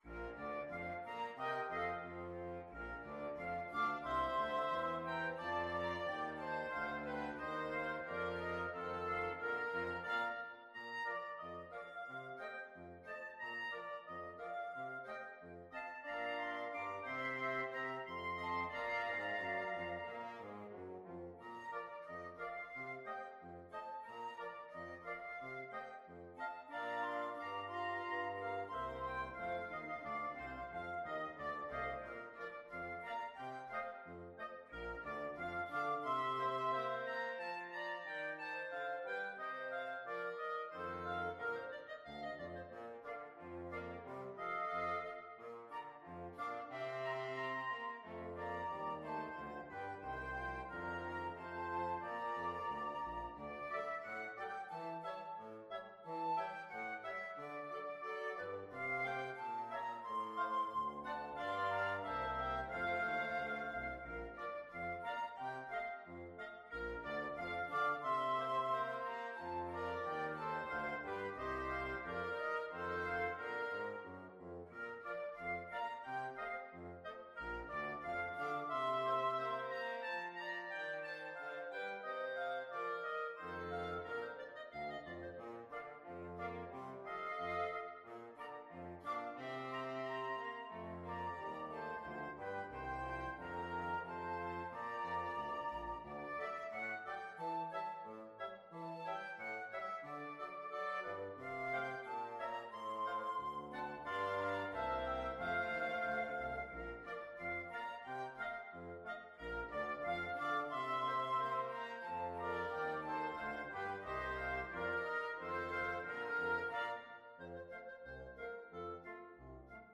FluteOboeClarinetFrench HornBassoon
=90 Fast and cheerful
2/2 (View more 2/2 Music)
Pop (View more Pop Wind Quintet Music)